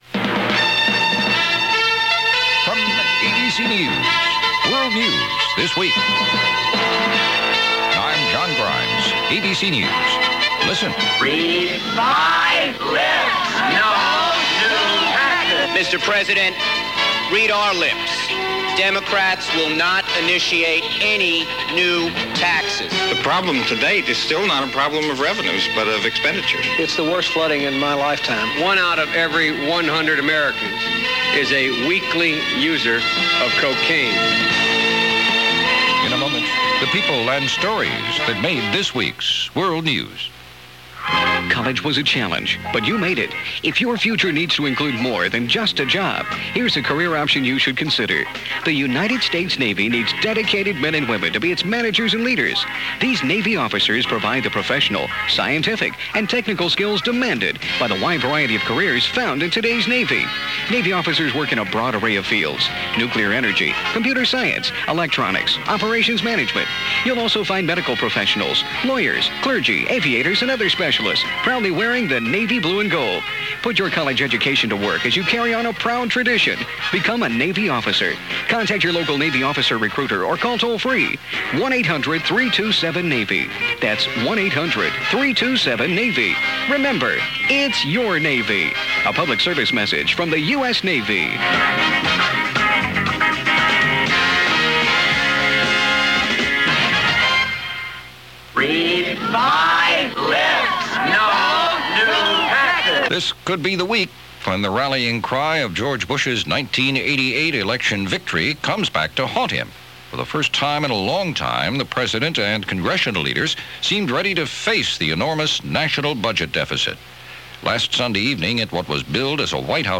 May 13, 1990 – World News This Week – ABC Radio